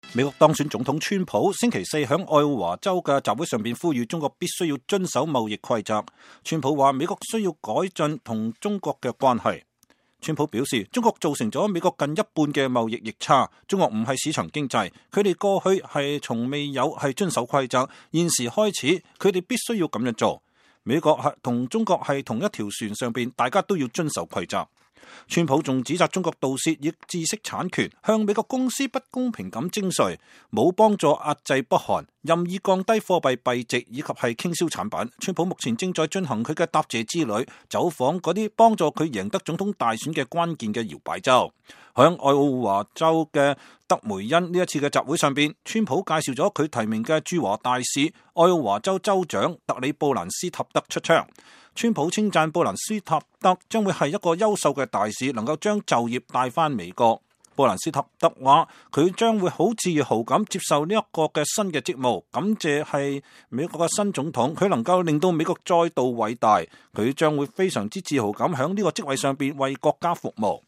美國當選總統川普星期四在愛奧華州的集會上呼籲中國必須遵守貿易規則。川普說美國需要改進與中國的關係。